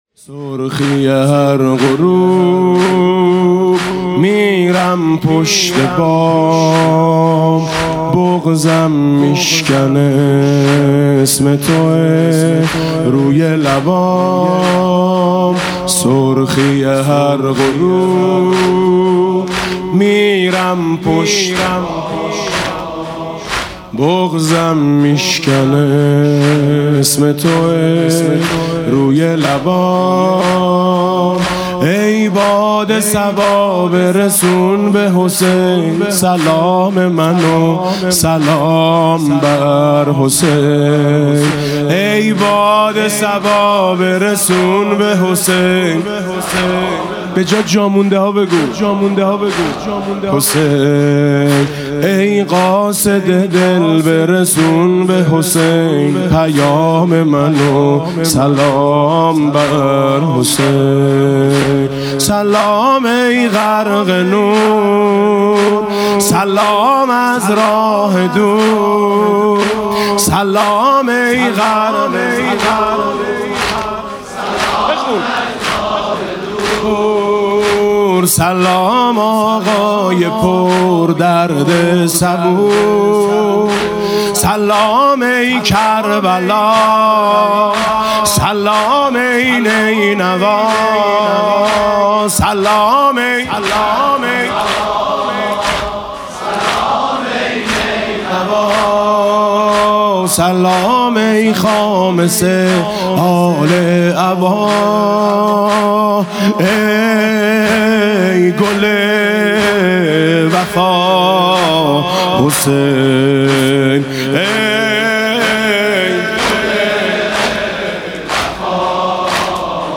مسیر پیاده روی نجف تا کربلا [عمود ۹۰۹]
مناسبت: ایام پیاده روی اربعین حسینی